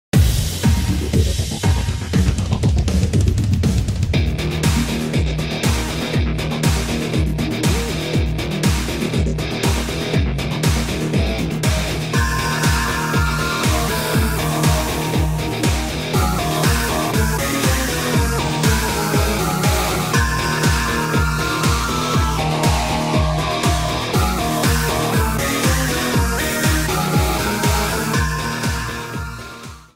Trimmed to 30 seconds, with a fade-out effect